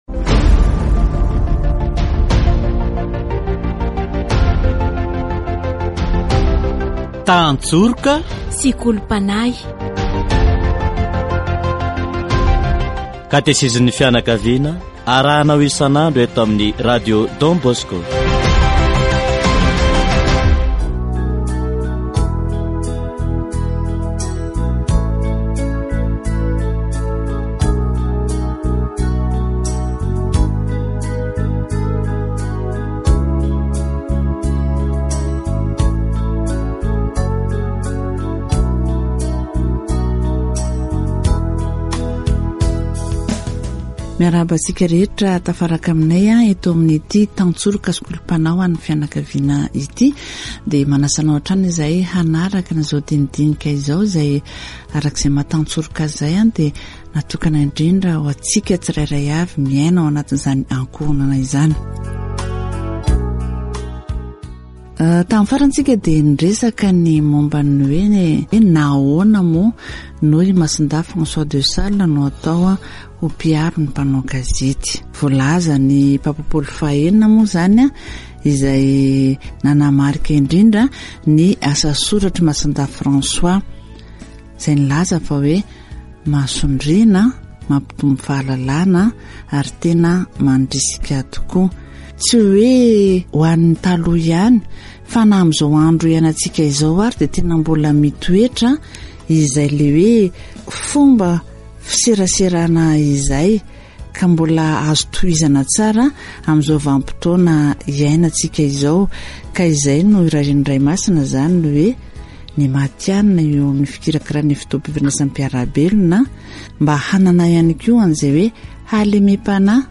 Avy amin'ny fahaiza-mihaino am-pitandremana no teraka ny "fandraisam-pitenenana mendrika". Tena ilaina ny fomba fifaneraserana mandona ny fo toy ny diloilo manasitrana. Katesizy momba ny andro manerantany ho an'ny fifandraisan'ny mpiara-belona